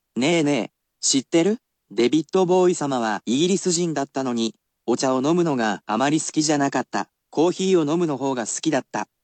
[casual speech + basic polite]